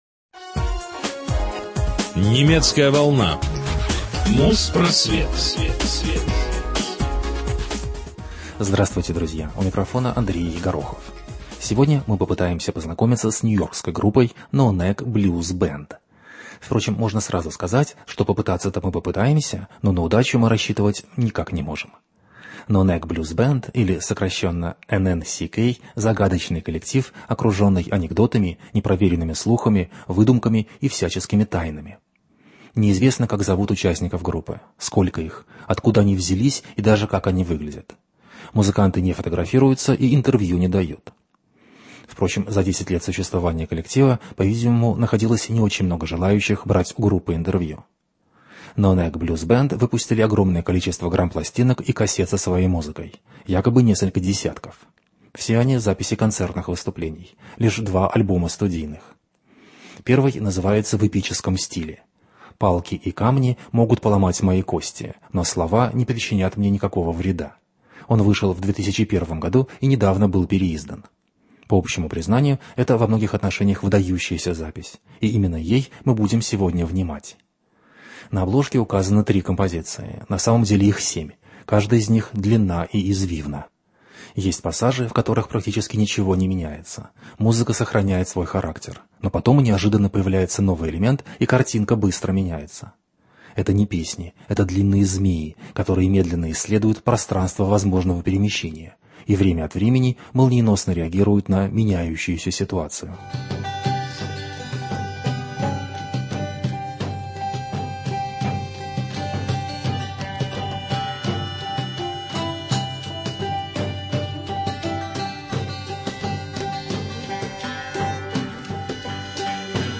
Психофолк-коллектив